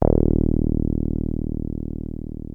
303 D#1 5.wav